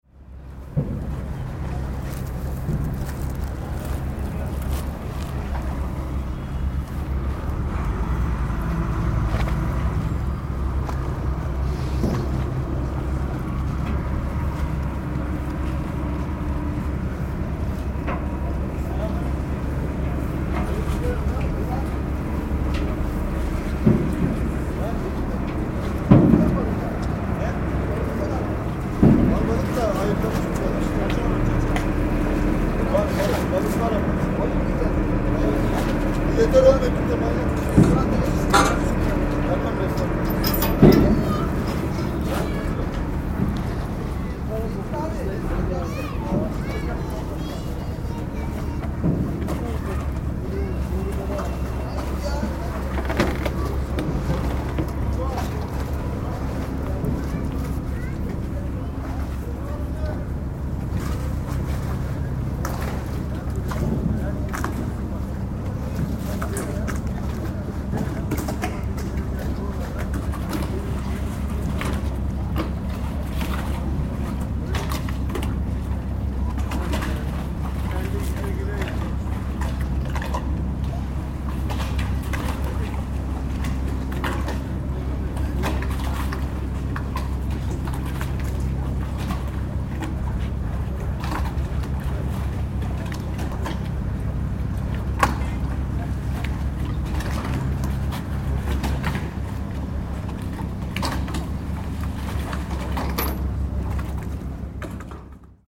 This is a simple recording of the general ambiance on deck while we are still connected to the pier. After 1’00 you’ll hear the sea water splashing and echoing beneath a small catamaran tied to the pier (in the second picture), something I tried to get to the foreground by stretching over the railing.